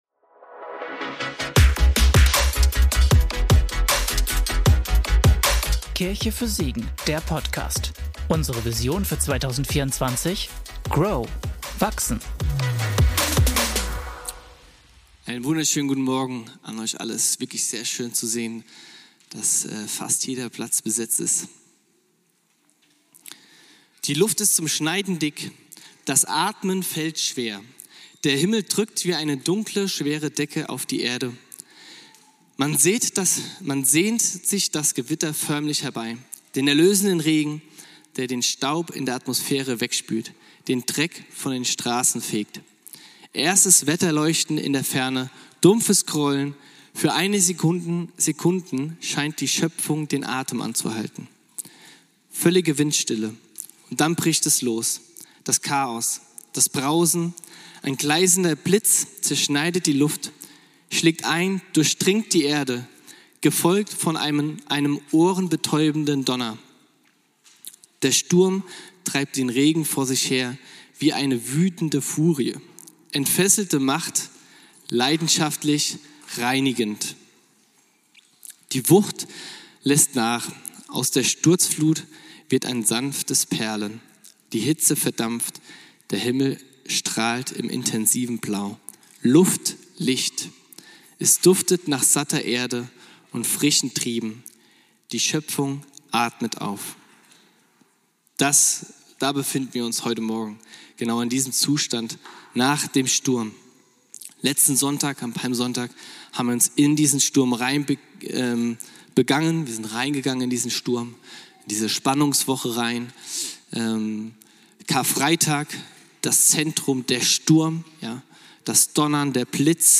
Gott stellt seine Schöpfung wieder her - Predigtpodcast